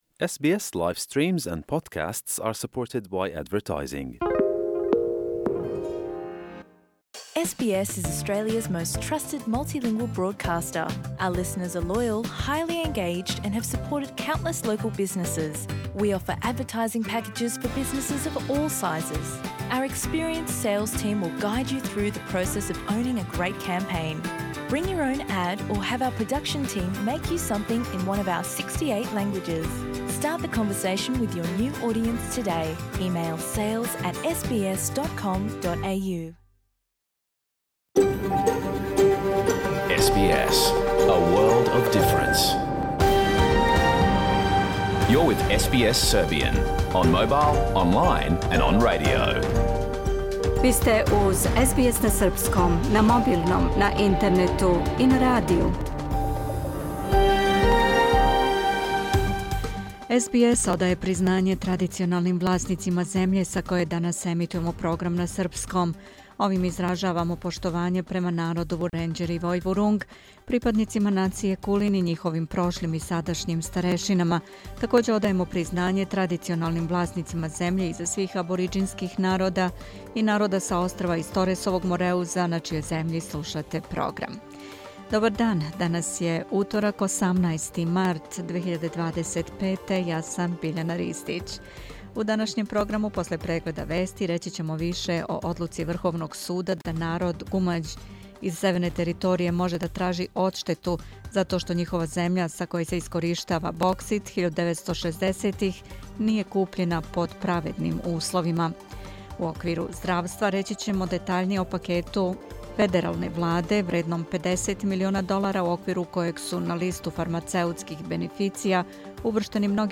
Serbian News Bulletin